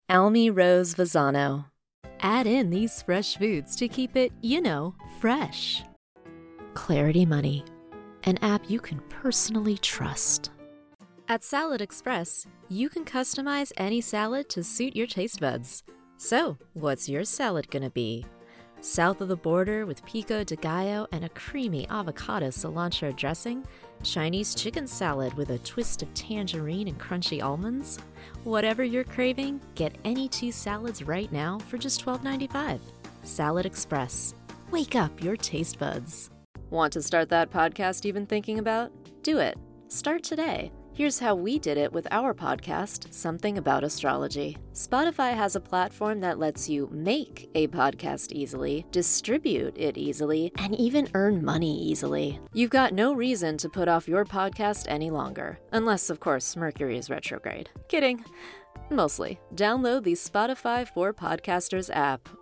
Commercial VO Demo Reel